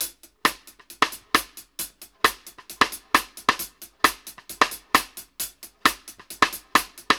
X-STIC+HAT-R.wav